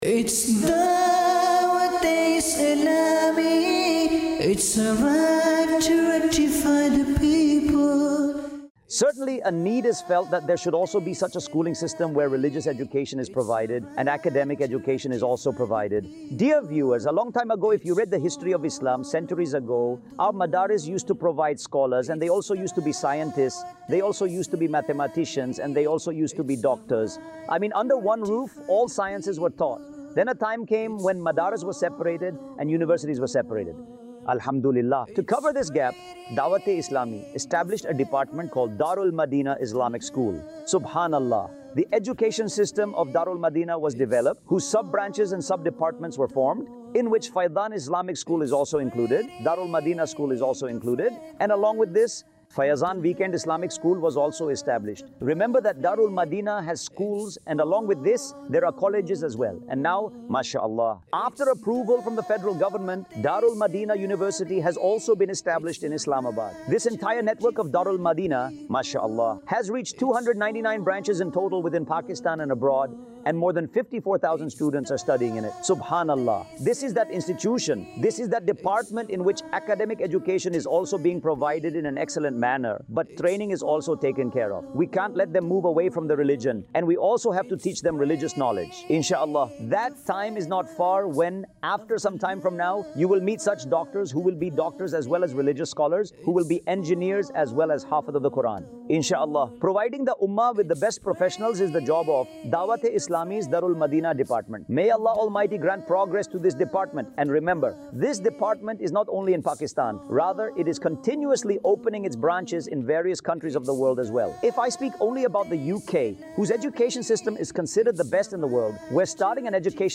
khutba
Dar-ul-Madinah | Department of Dawateislami | Documentary 2026 | AI Generated Audio
دارالمدینہ | شعبہِ دعوت اسلامی | ڈاکیومینٹری 2026 | اے آئی جنریٹڈ آڈیو